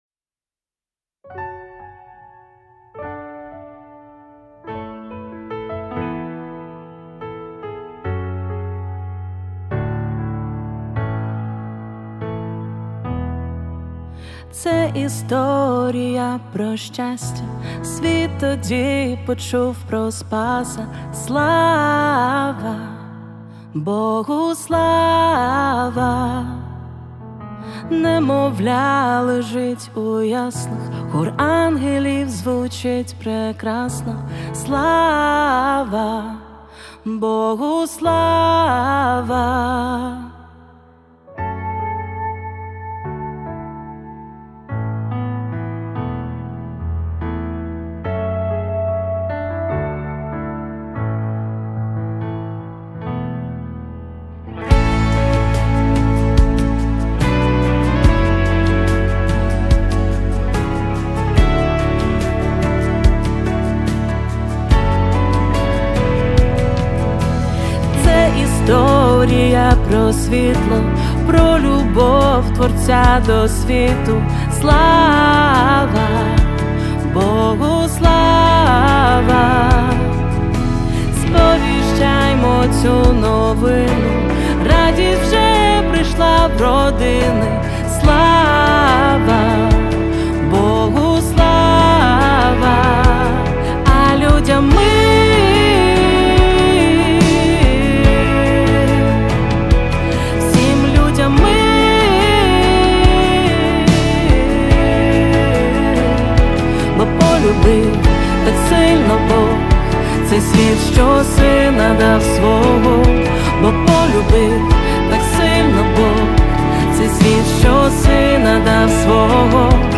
79 просмотров 118 прослушиваний 10 скачиваний BPM: 143